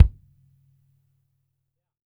BW KICK 01.wav